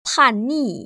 [pànnì] 판니